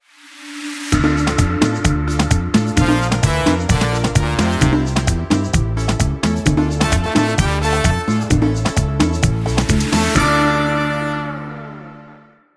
Music Genre: Merengue Pop (Instrumental)